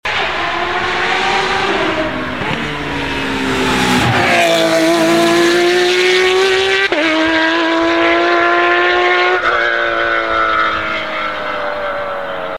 Porsche 911 RSR GTE sounds🥵 sound effects free download